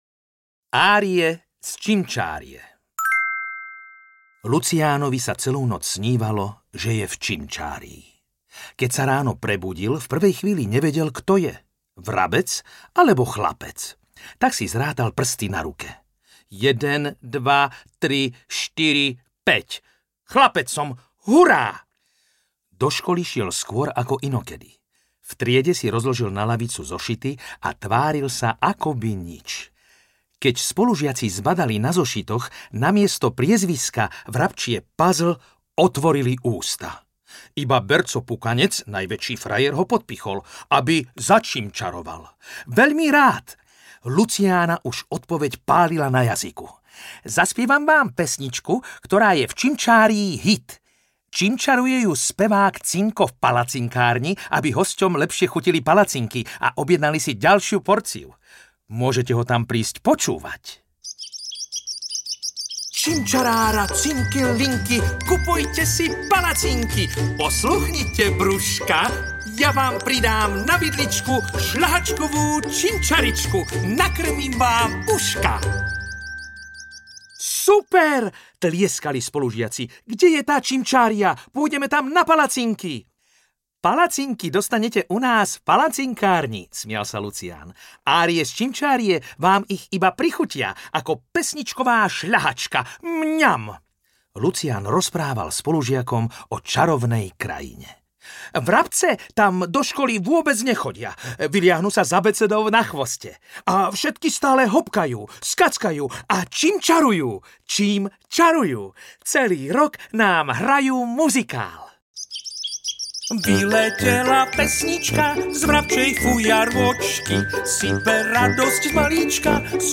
Čím čarujú vrabce audiokniha
Ukázka z knihy